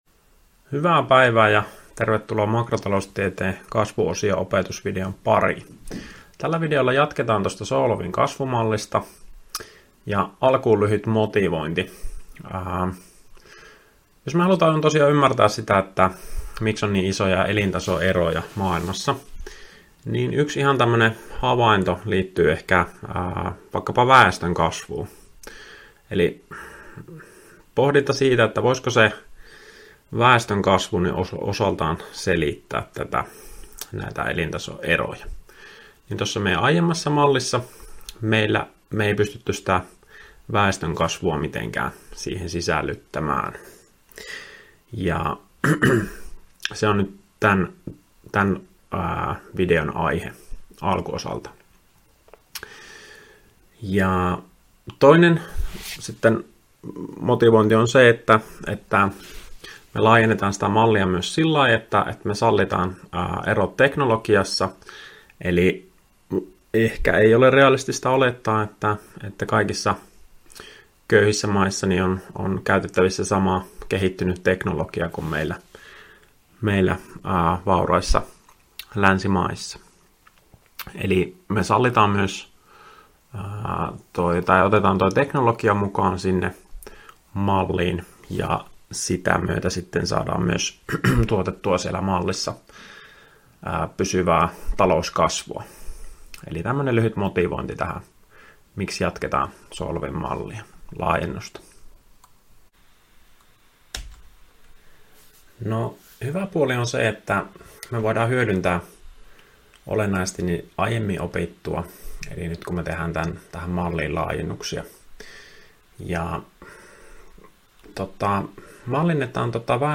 Opintojakson "Makrotaloustiede I" kasvuosion 3. opetusvideo